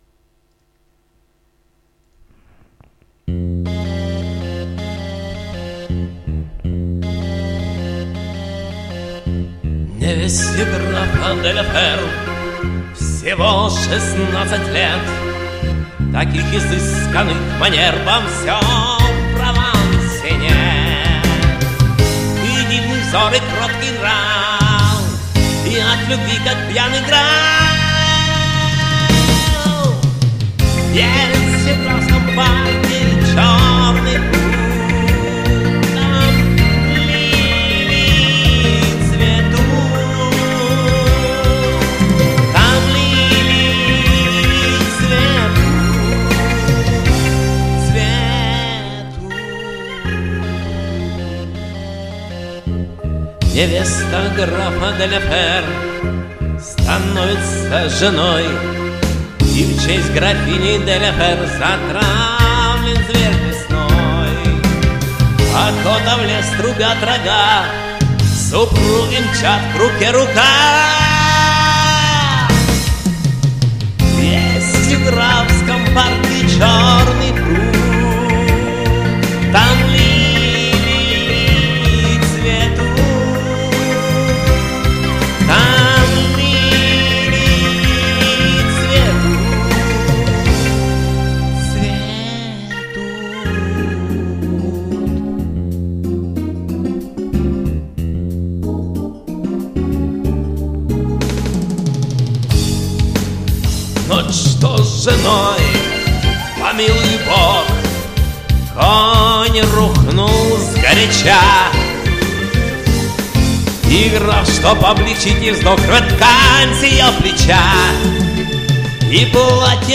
Особенно на стыке куплета-припева...